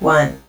Countdown01.wav